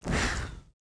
Index of /App/sound/monster/skeleton_wizard
walk_act_1.wav